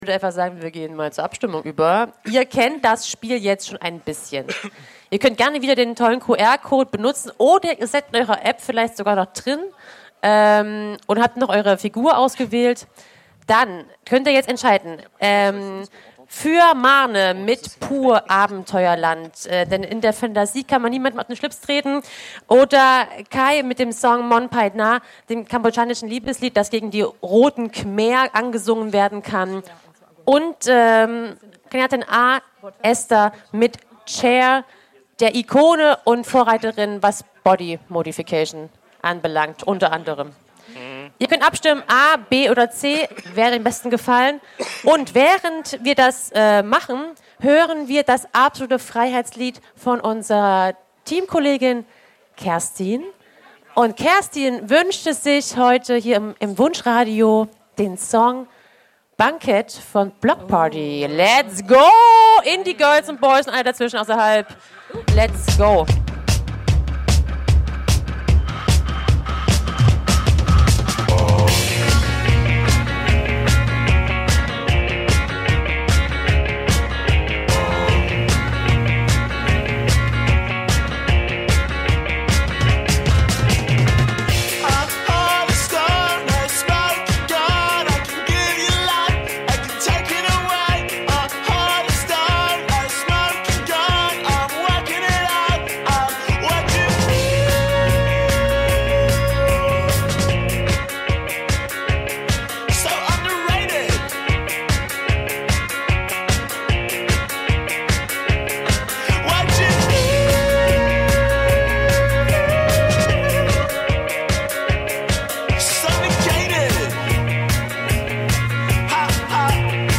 Aufzeichnung vom 24. Mai 2025 in der Kleinen Rampe Erfurt.